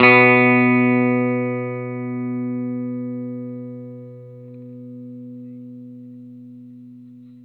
R12 NOTE  B.wav